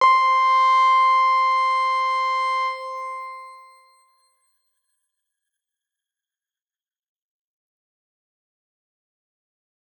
X_Grain-C5-pp.wav